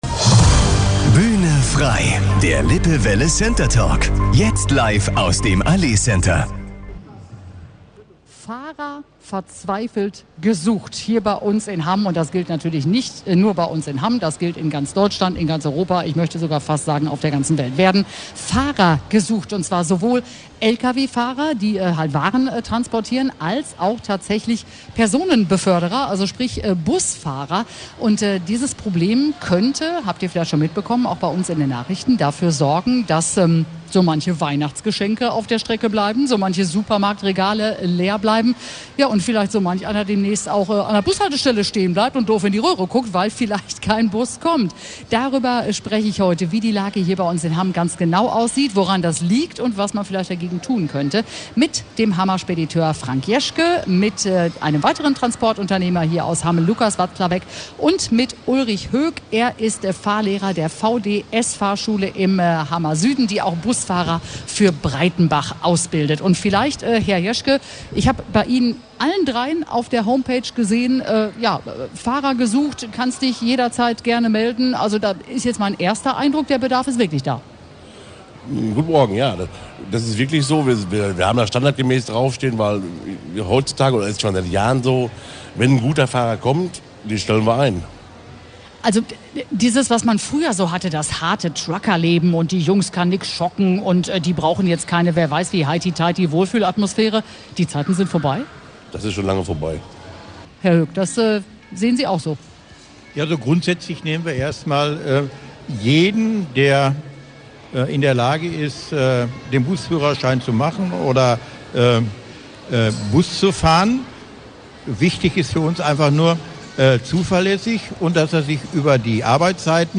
Einige Hammer Spediteure suchen gerade LKW-Fahrer. Und auch die Hammer Busunternehmen brauchen Unterstützung. Darum ging es am Samstag eine Stunde lang in unserer Sendung Bühne frei live aus dem Allee-Center.